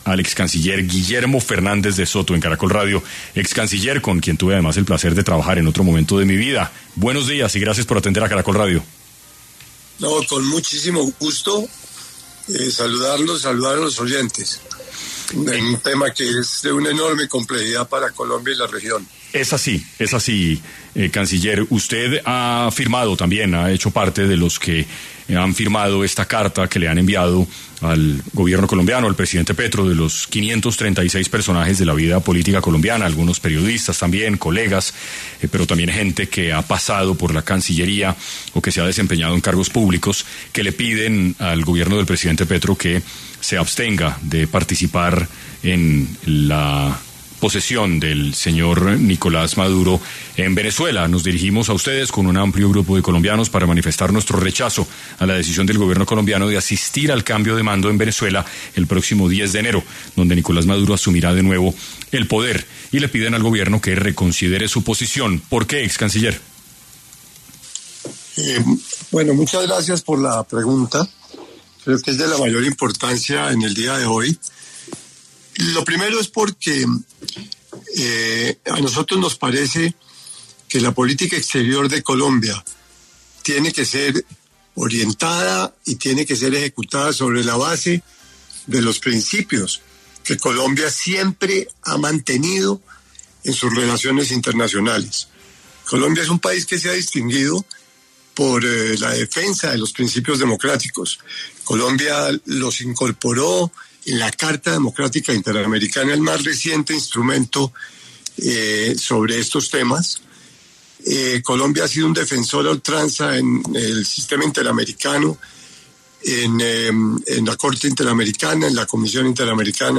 Guillermo Fernández de Soto, excanciller, habló en 6AM sobre, cuáles podrían ser las implicaciones de una posible asistencia de Gustavo Petro a la posesión de Nicolás Maduro